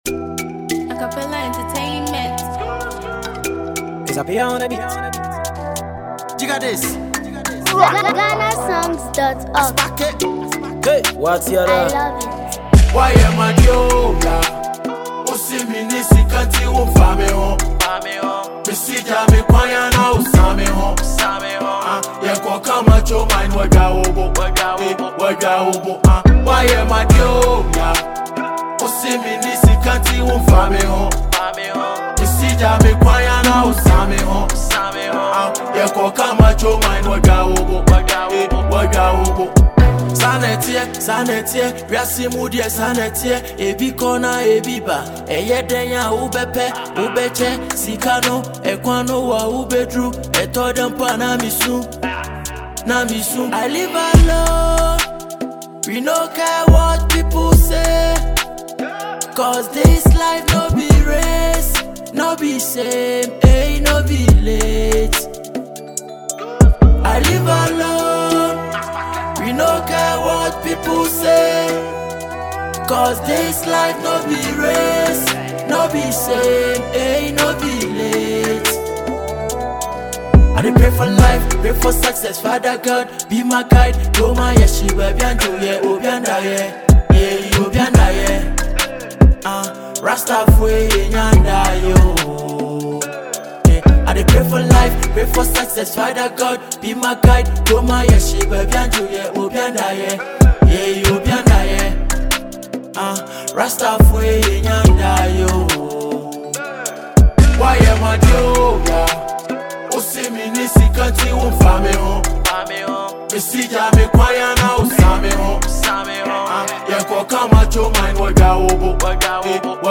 is the new powerful hip-hop anthem trending across Ghana.